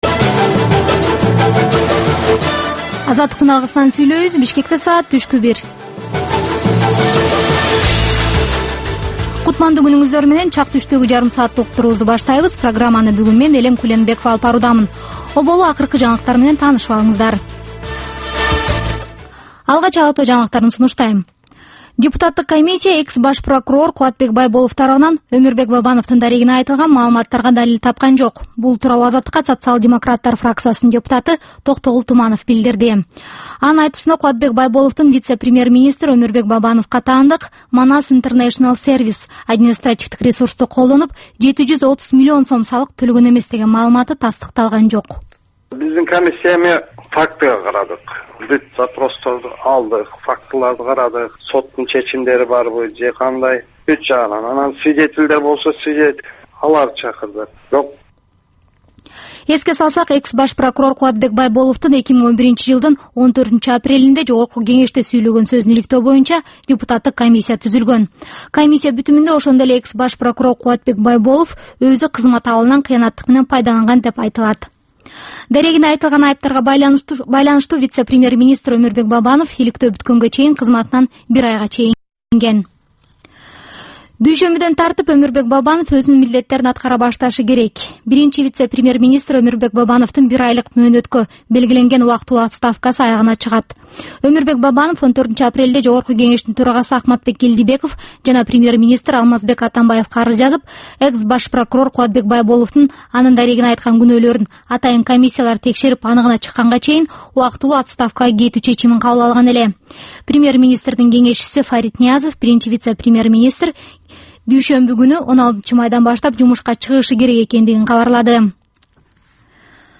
Түшкү саат 1деги кабарлар
"Азаттык үналгысынын" күндөлүк кабарлар топтому Ала-Тоодогу, Борбордук Азиядагы жана дүйнөдөгү эң соңку жаңылыктардан турат. Кабарлардын бул топтому «Азаттык үналгысынын» оригиналдуу берүүсү обого чыккан сааттардын алгачкы беш мүнөтүндө сунушталат.